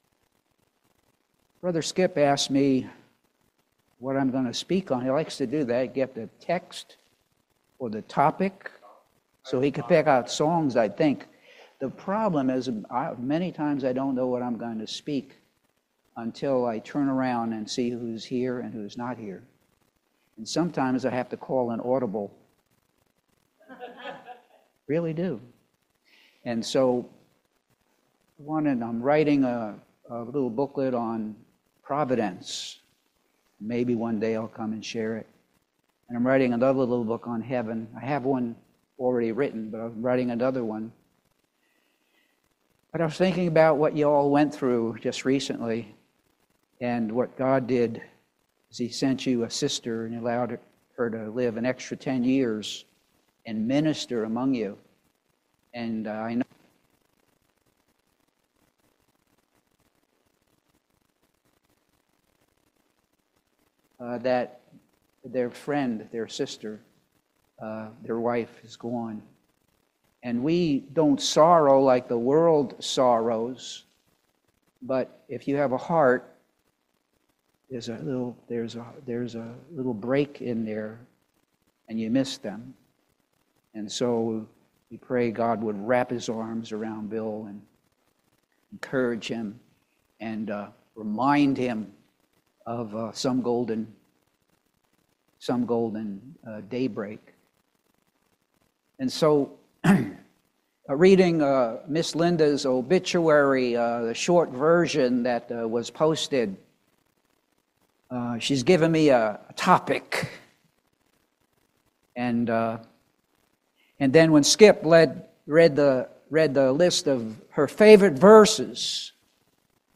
John 1:1-5 Service Type: Family Bible Hour The Bible is the answer to all your problems.